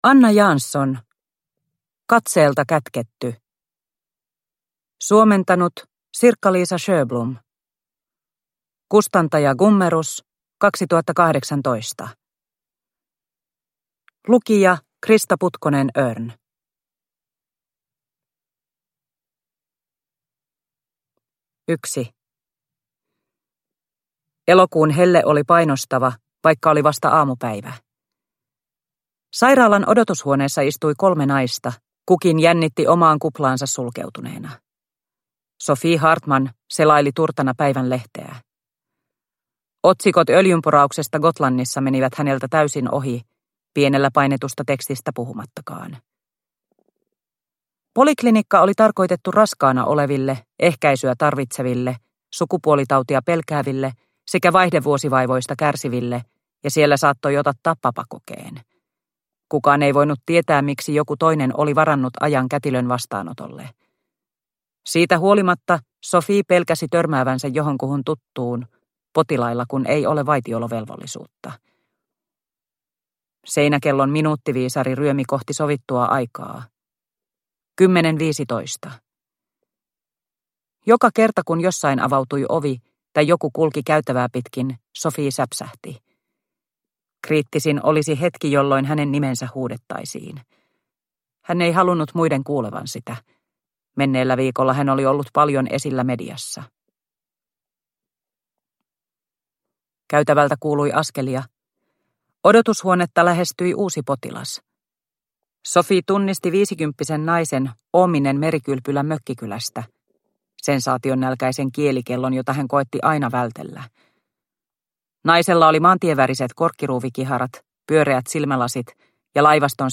Katseelta kätketty – Ljudbok – Laddas ner